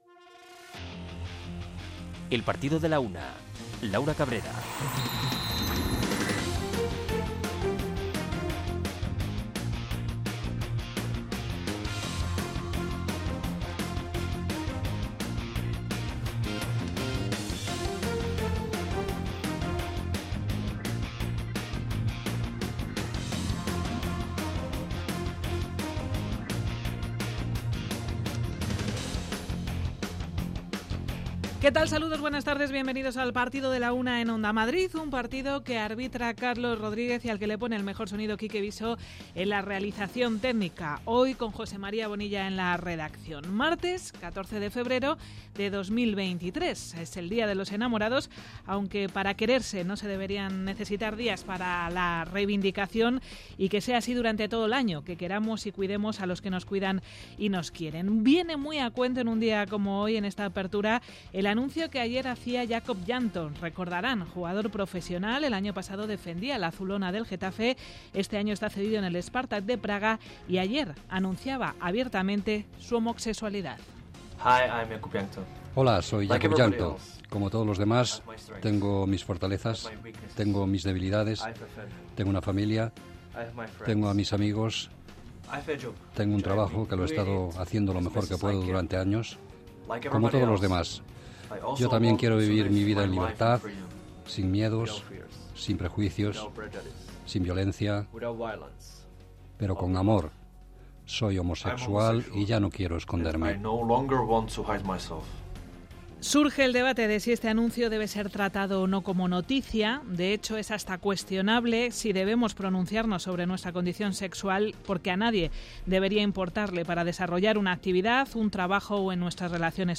Iniciamos nuestro recorrido escuchando a Carlo Ancelotti. El entrenador del Real Madri hace repaso de la actualidad del Real Madrid en la previa del partido que les mide al Elche, aplazado por la disputa del Mundial de Clubes. Tres meses después regresa la Liga de Campeones con la disputa de los octavos de final y lo hace con un gran partido entre el PSG y el Bayern de Múnich.